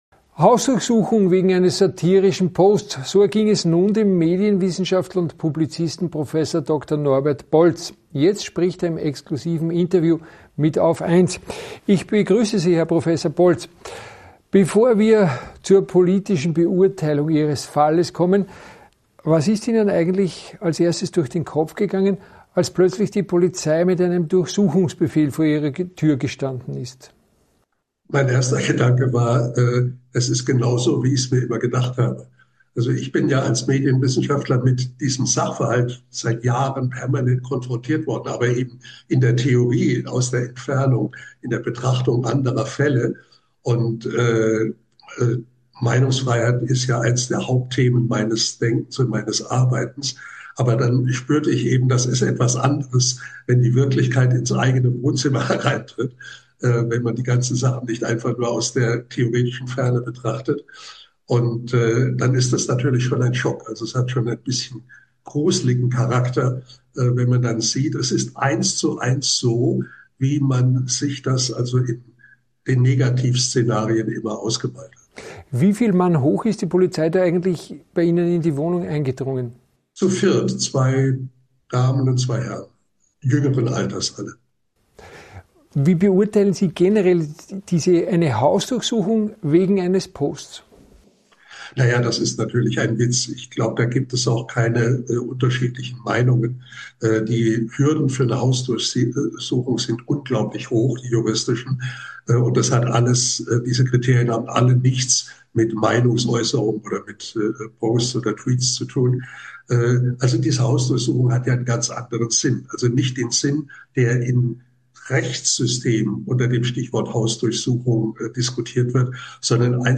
Jetzt spricht er im exklusiven Interview bei AUF1.